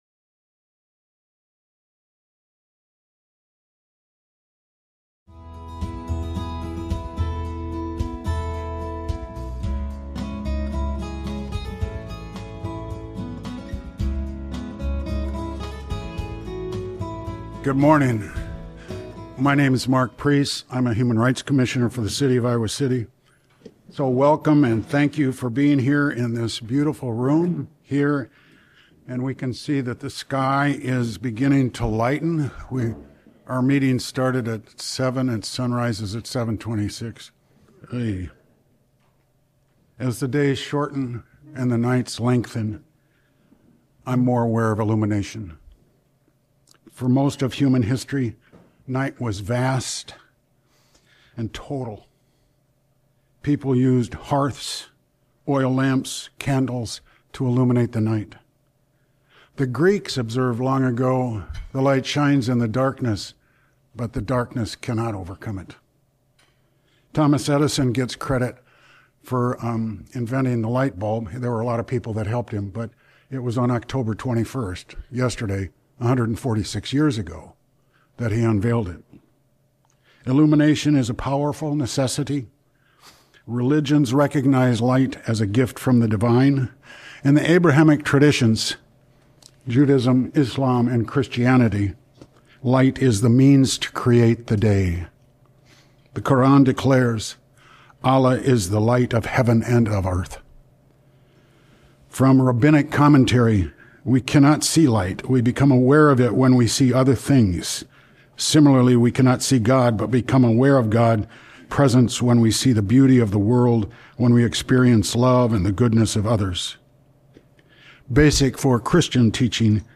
41st Annual Human Rights Awards Breakfast